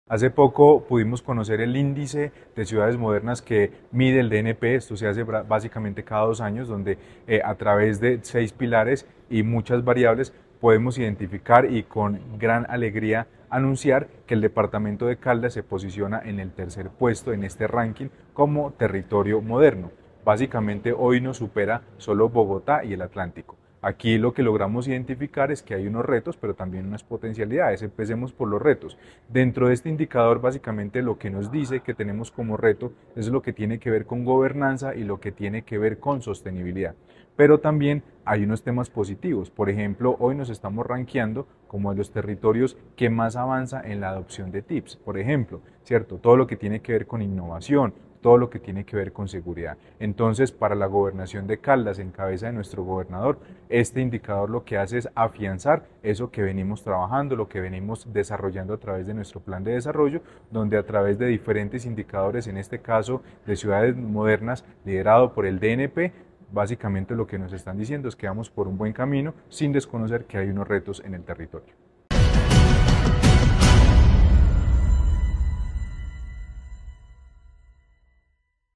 Carlos Anderson García, secretario de Planeación de Caldas.